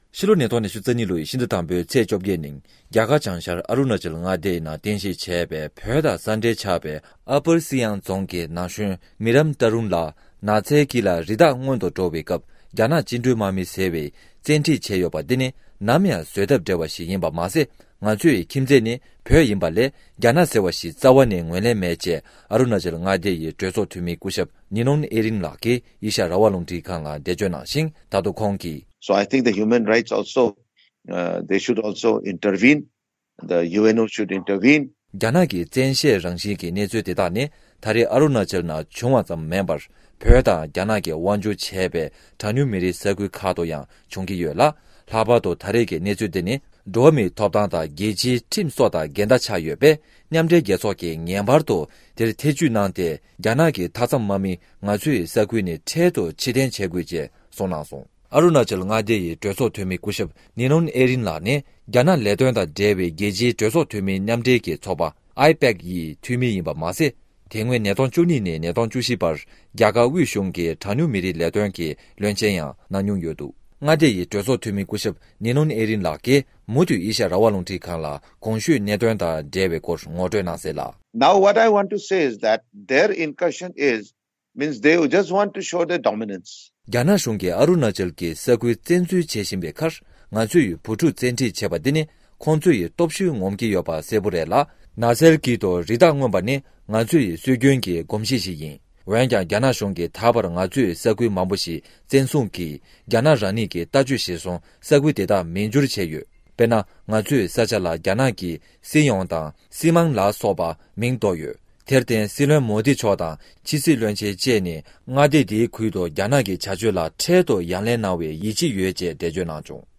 རྒྱ་ནག་དམག་མིས་ཨ་རུ་ན་ཅལ་མངའ་སྡེའི་ན་གཞོན་ཞིག་བཙན་ཁྲིད་བྱས་པ་དེ་ནི་རྒྱལ་སྤྱིའི་ཁྲིམས་དང་འགལ་ཡོད་པས་མཉམ་འབྲེལ་རྒྱལ་ཚོགས་ཀྱིས་ངེས་པར་དུ་ཐེ་བྱུས་དགོས་པ་ཨུ་རུ་ན་ཅལ་མངའ་སྡེ་གྲོས་ཚོགས་འཐུས་མི་ཞིག་གིས་འདི་ག་རླུང་འཕྲིན་ཁང་གུ་འགྲེལ་བརྗོད་གནང་བའི་སྐོར།